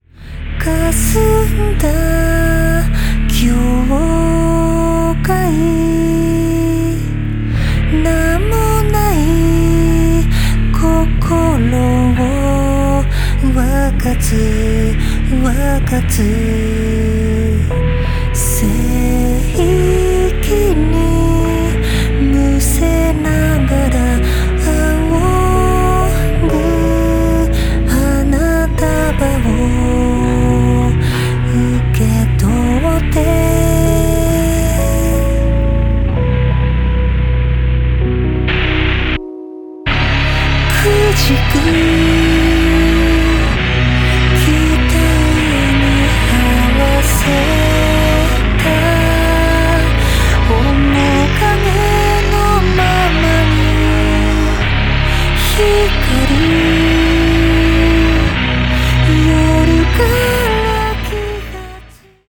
ギターはいつも通りLow-Dチューニング、
ベースがLow-Bチューニング